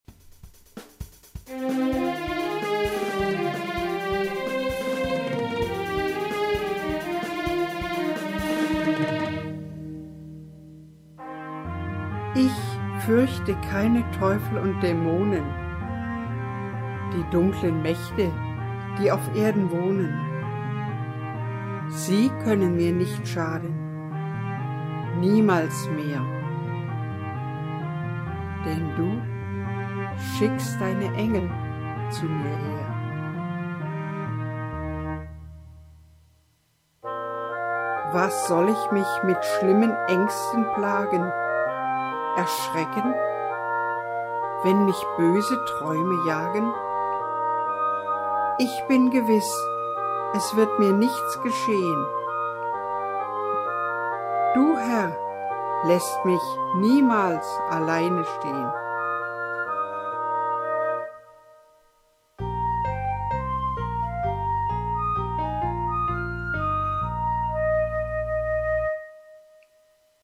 Am Ende der Seite wird das Gedicht mit Musikbegleitung rezitiert. Wallfahrtskirche Neusass Ich fürchte keine Teufel und Dämonen, die dunklen Mächte, die auf Erden wohnen; sie können mir nicht schaden, niemals mehr, denn du schickst deine Engel zu mir her.
Gedichtrezitation mit Begleitmelodie